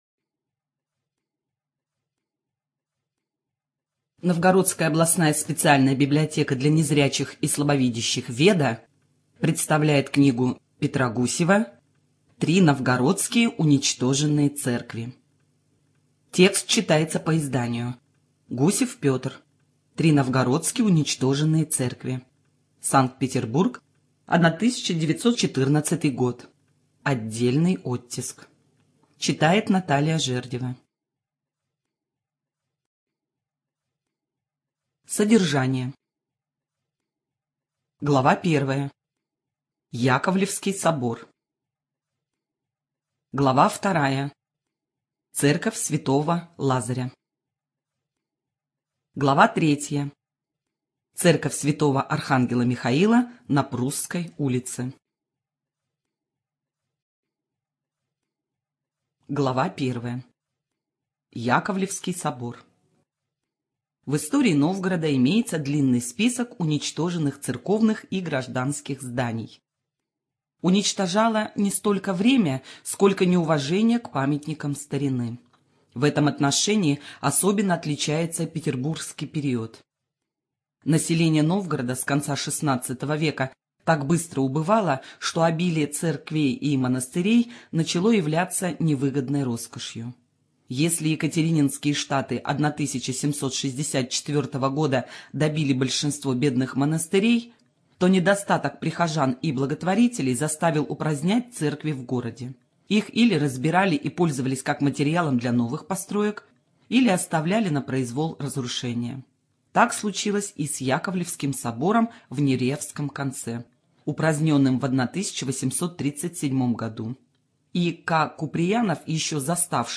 Студия звукозаписиНовгородская областная библиотека для незрячих и слабовидящих "Веда"